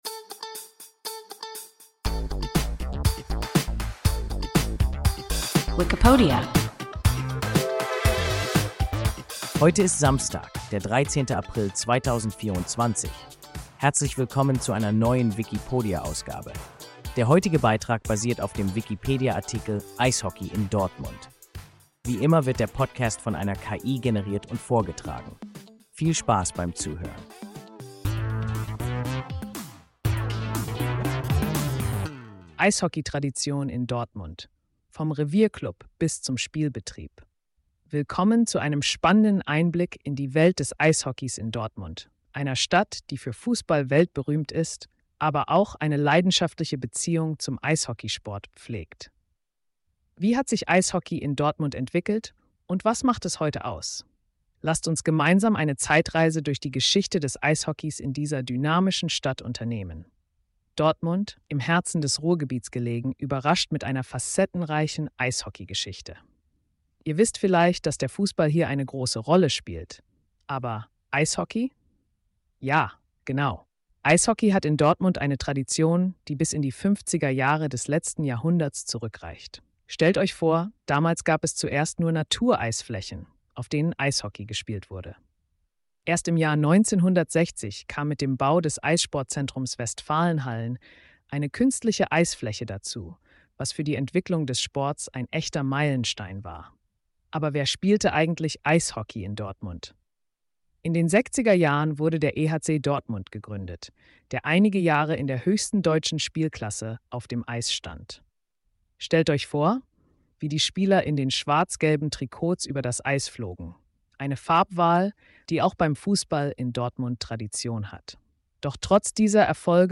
Eishockey in Dortmund – WIKIPODIA – ein KI Podcast